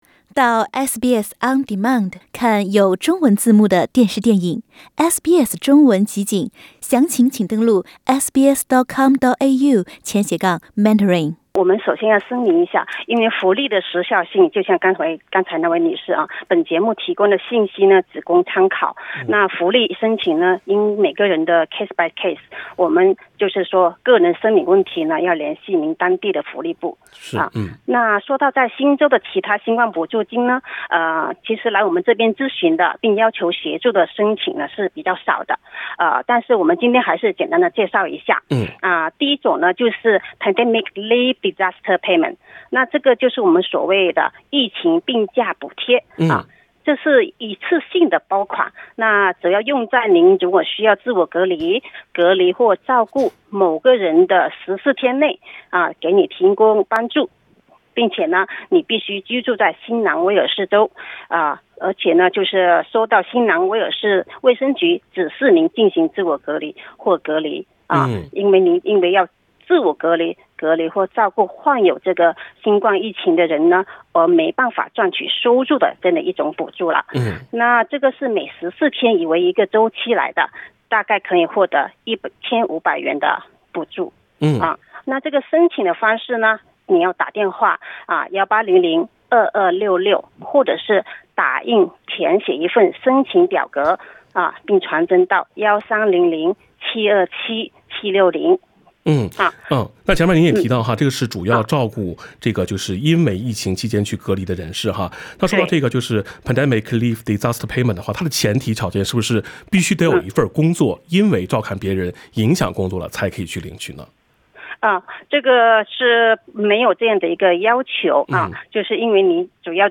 新冠疫苗接種率不僅決定了解封進度，也影響福利金髮放，除了“新冠危機補助金“（ Covid Disaster Payment），還有其他哪些疫情福利金可以領取？（點擊封面圖片，收聽熱線回放）